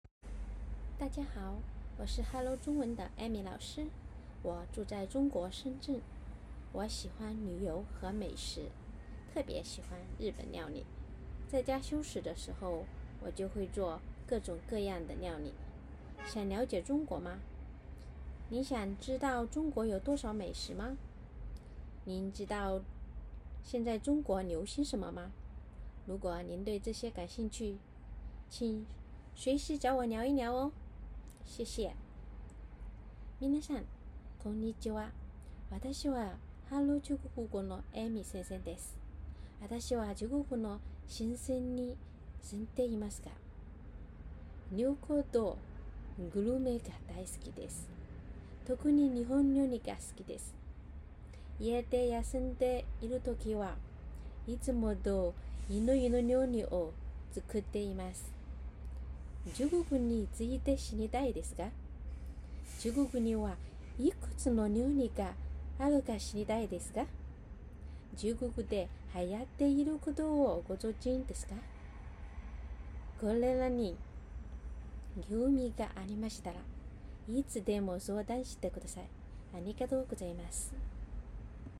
音声の自己紹介